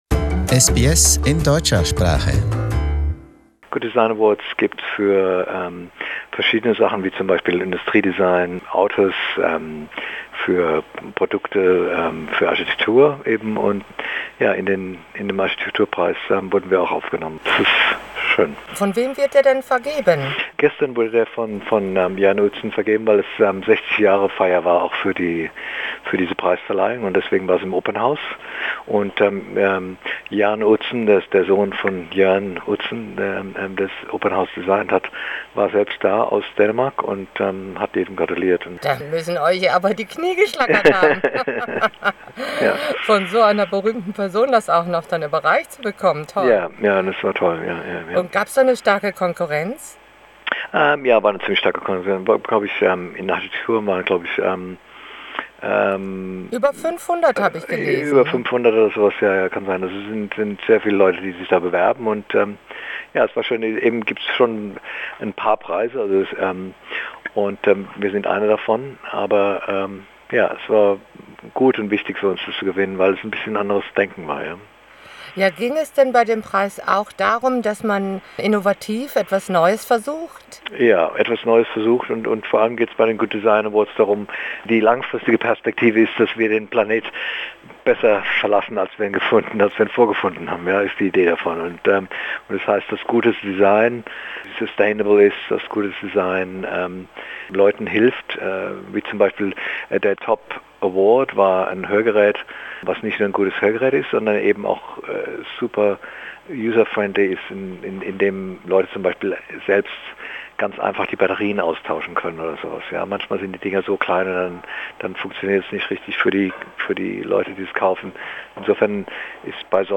In conversation: GISS wins prestigious Australian Good Design Award